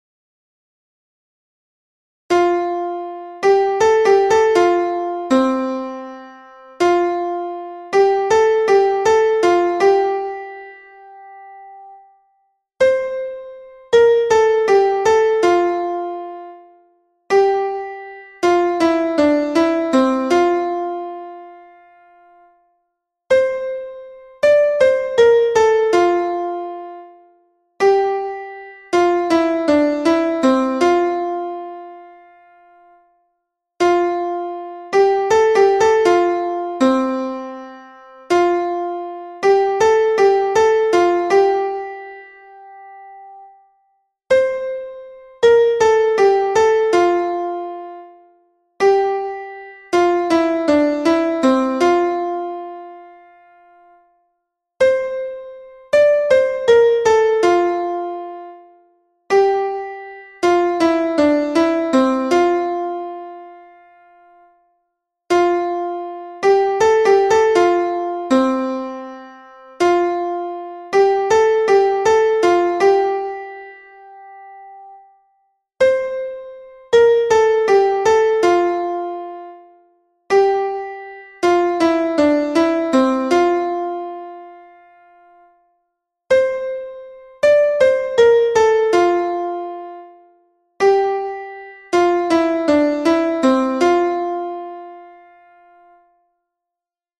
solo-midi (sopranes/ténors)
Tece_voda_tece-solo.mp3